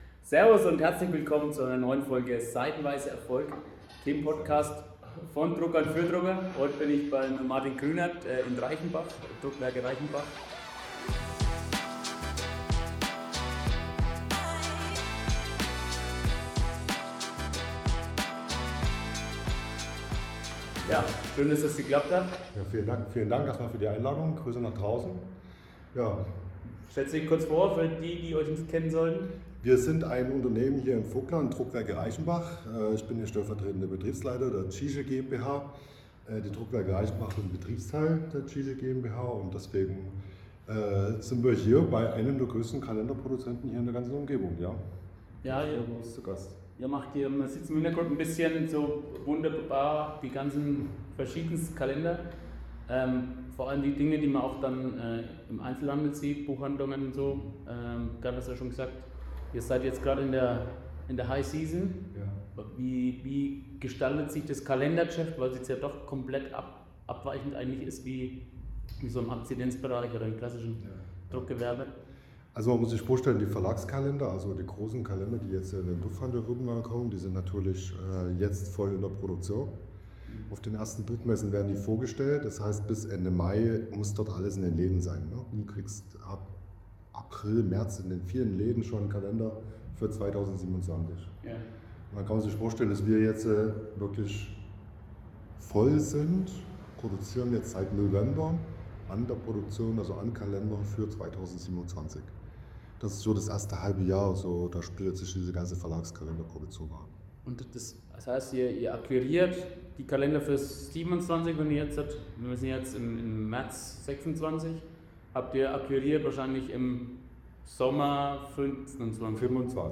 Wir haben gemeinsam überlegt und diskutiert – was braucht die Ausbildung in unserer Branche heute und was muss heute für die Zukunft geändert werden. Ein sehr vielseitiges Gespräch über eine spannende Firmengeschichte, eine beeindruckende Produktion und wichtige Gedanken über die Ausbildung in unserer Branche.